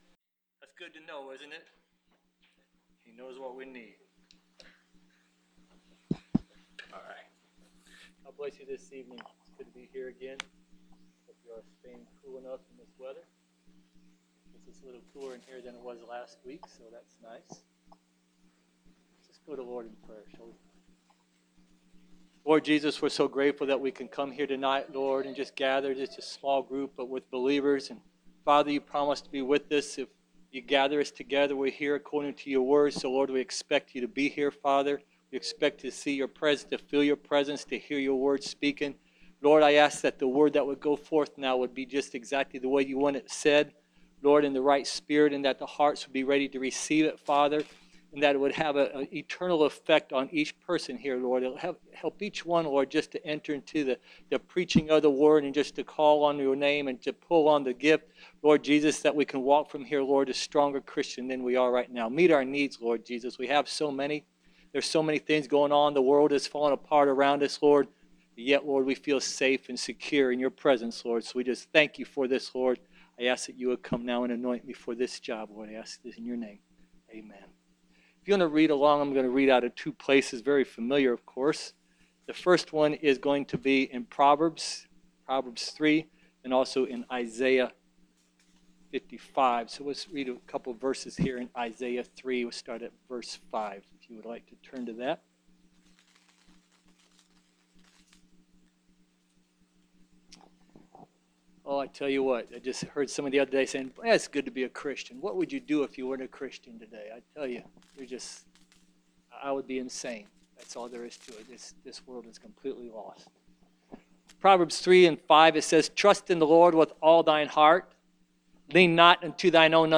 Preached July 13, 2023